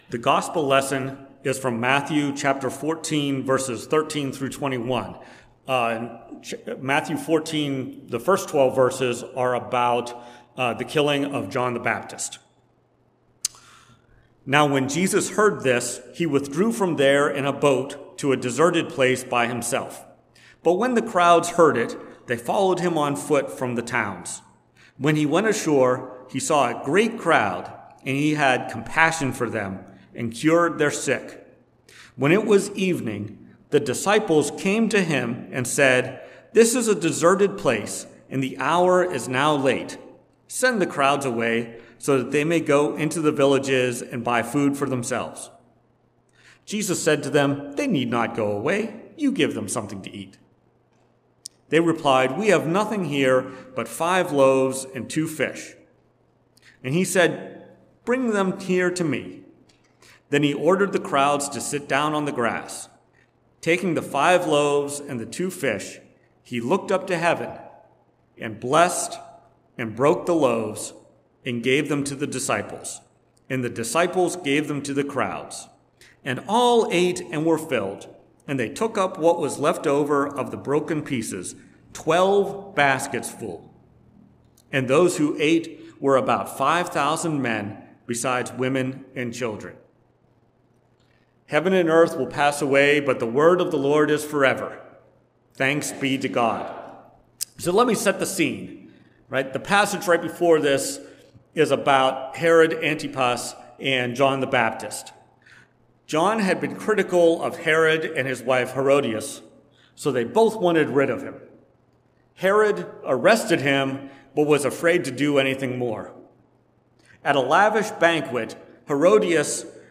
Preached on August 6, 2023, at First Presbyterian Church of Rolla. Based on Matthew 14:13-21.